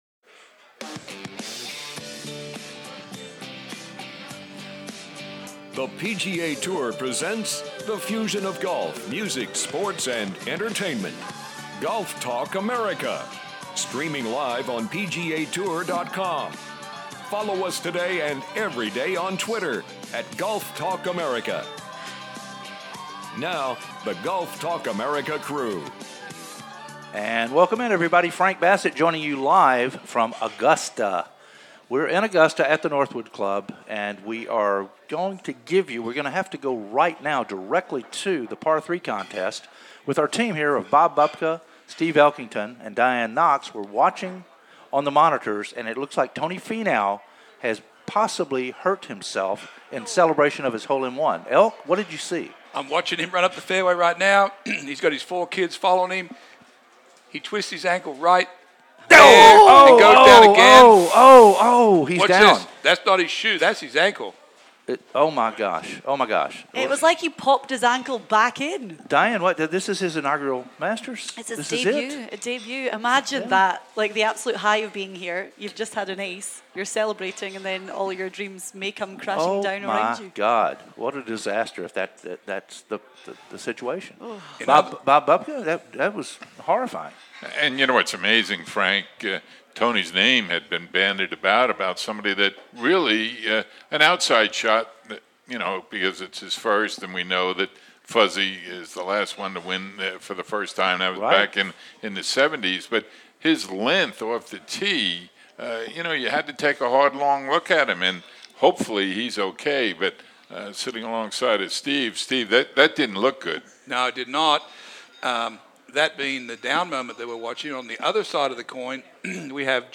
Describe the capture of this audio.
are LIVE from The Masters